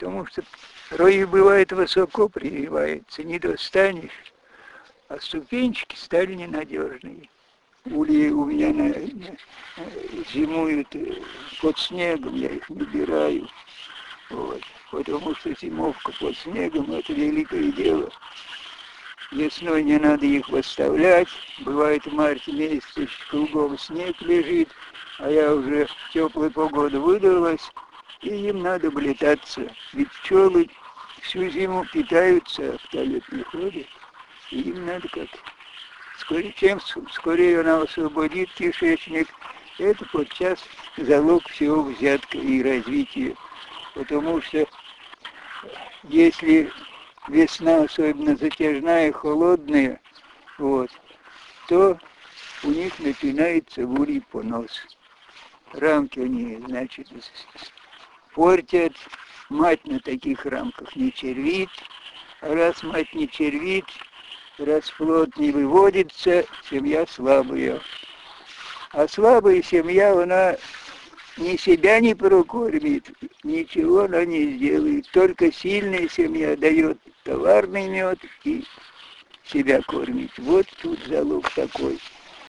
Владимирская область, Селивановский район
Рассказ